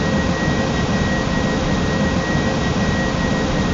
Add wing aft sounds
v2500-whine.wav